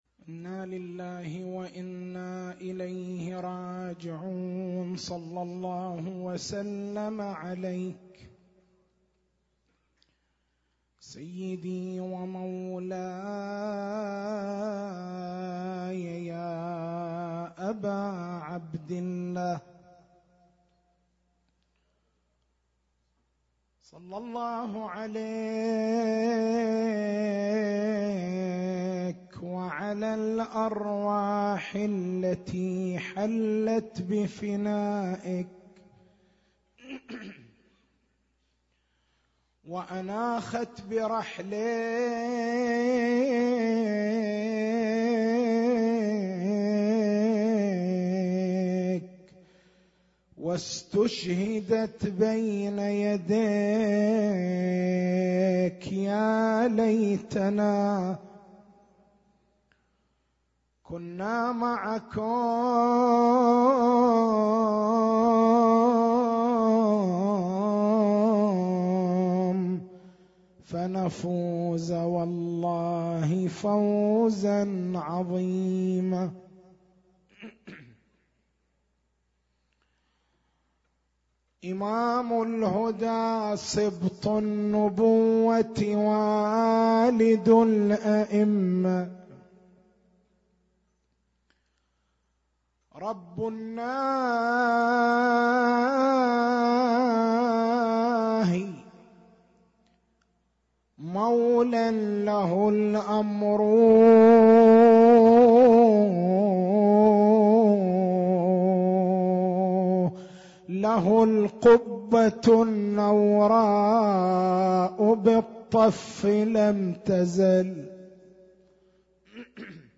تاريخ المحاضرة
حسينية الزين بالقديح